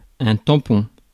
Ääntäminen
US : IPA : [ʍɒd]